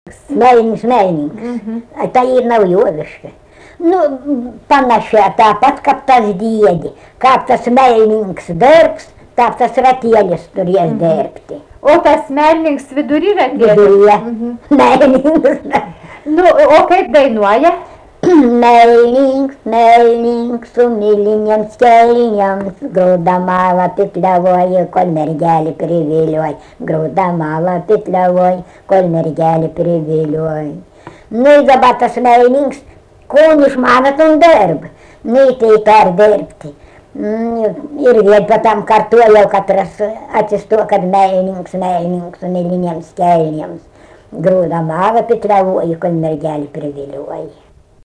Dalykas, tema daina
Erdvinė aprėptis Barvydžiai
Atlikimo pubūdis vokalinis